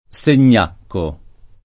[ S en’n’ # kko ]